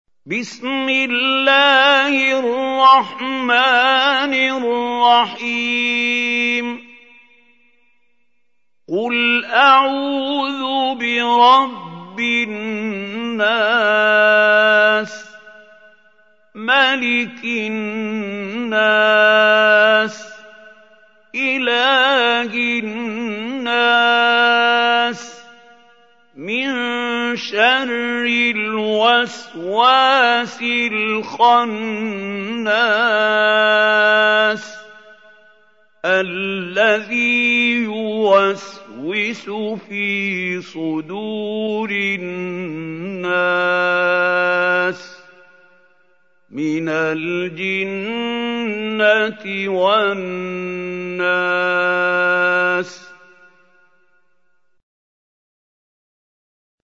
Чтение Корана > ХАЛИЛ ХУСАРИ